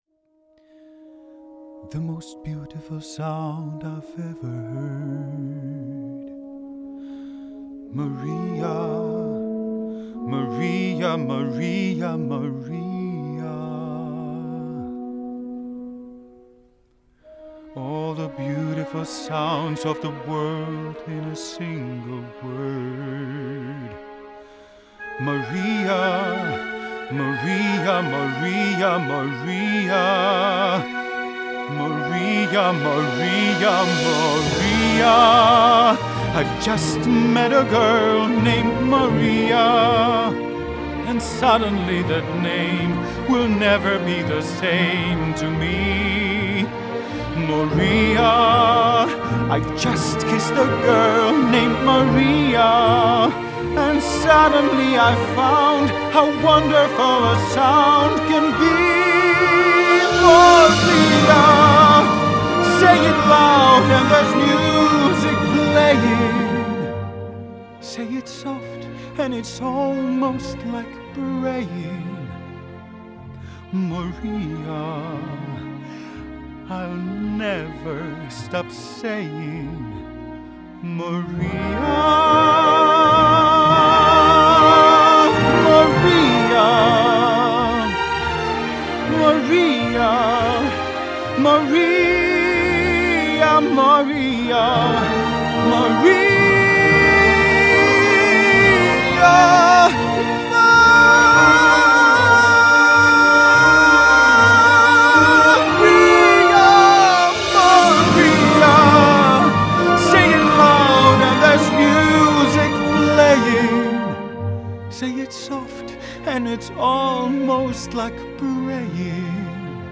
Male Voice (Unprocessed)
Thanks to tube based design, your voice will be on face and slightly compressed and will sit nicely in a mix.
Male-Voice.wav